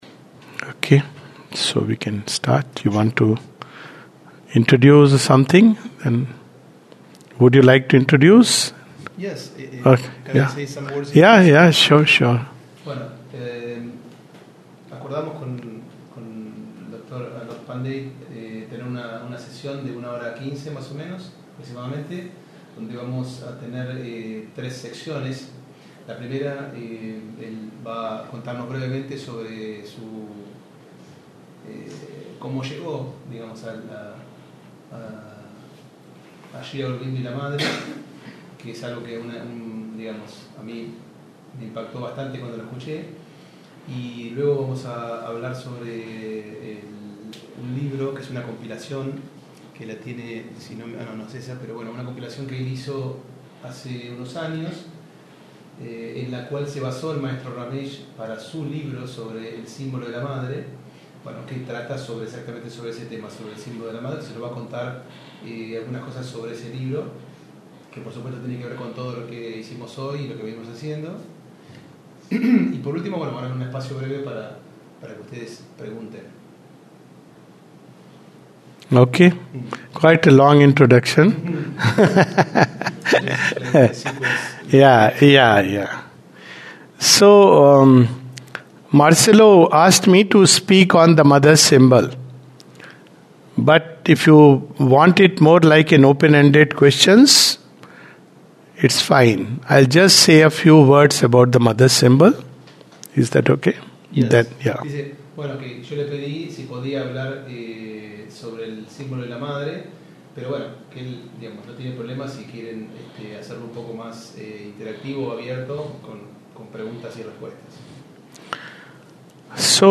The Mother's Symbol | TE 610 (with Spanish translation)
This is a talk with a group of devotees from Argentina on The Mother's Symbol and questions related to Yoga.